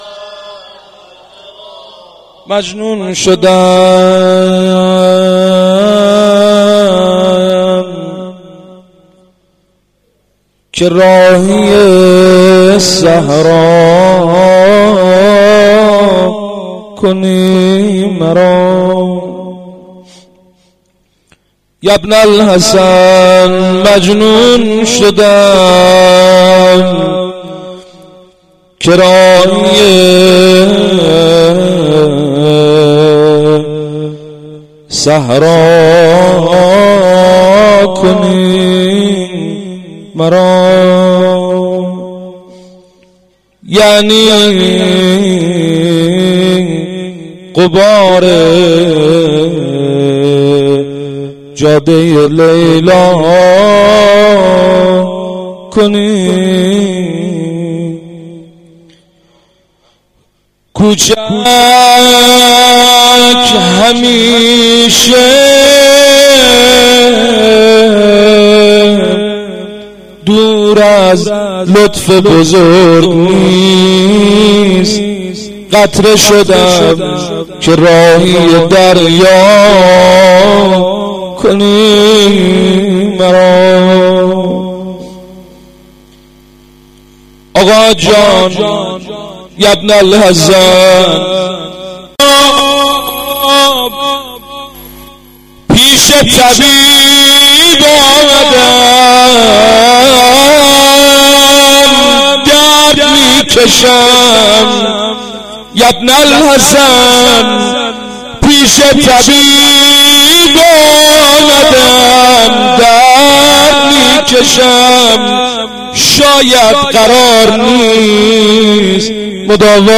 هیئت جوادالائمه شهرستان کمیجان
روضه-امام-جواد.mp3